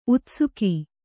♪ utsuki